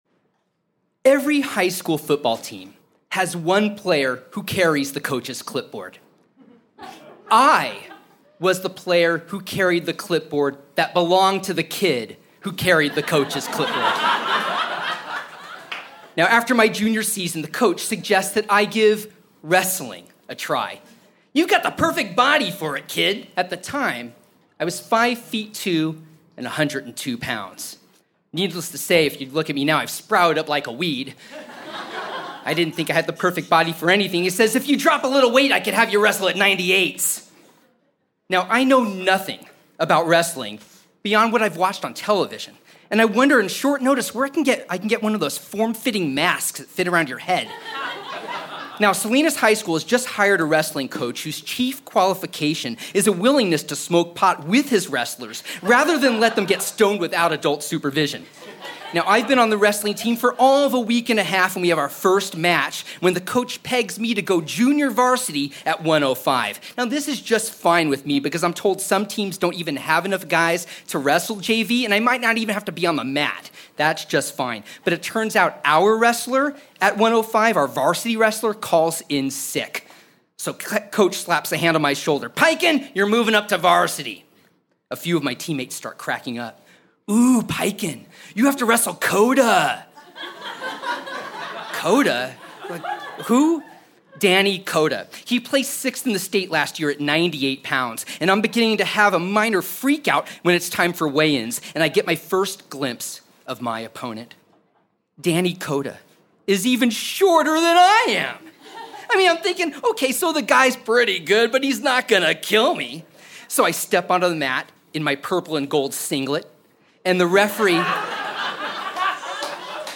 The Fine Art of EscapeI told this story through Minnesota Public Radio’s “In The Loop” storyslams (both the show and segment are, alas, defunct).